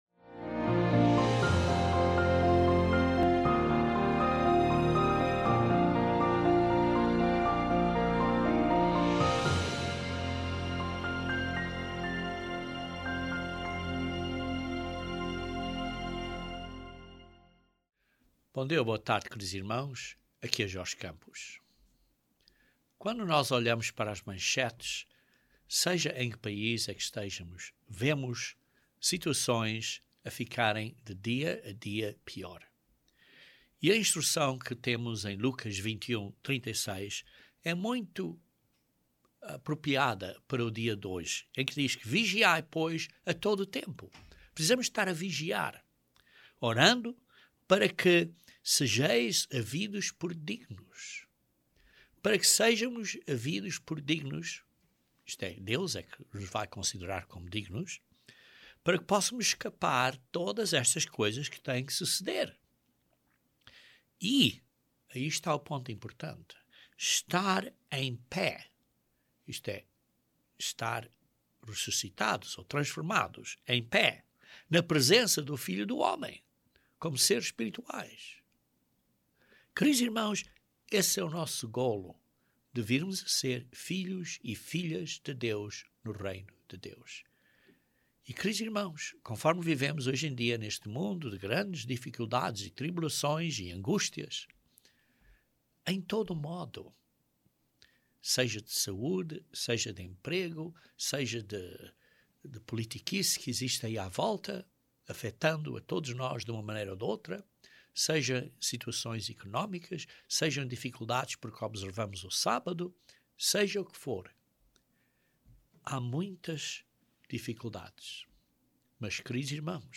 Sermão